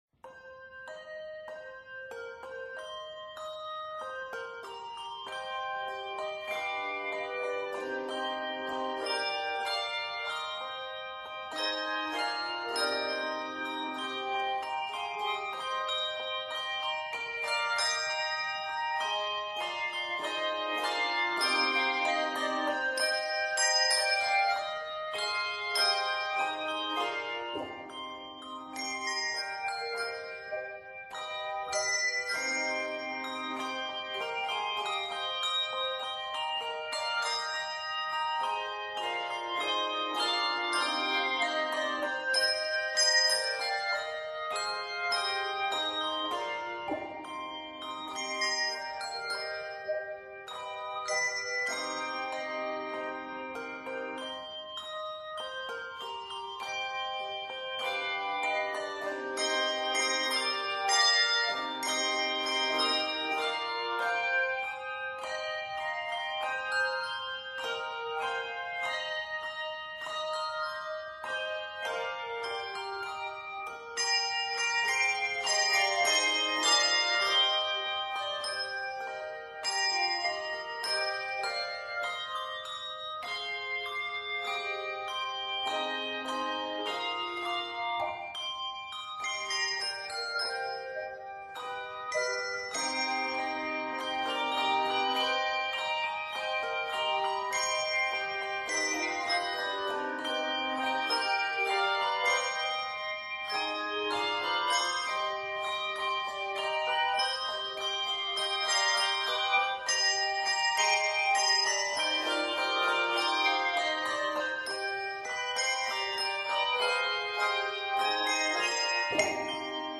three minutes of uplifting, joyous ringing
jazzy treatment
traditional shape-note hymn tune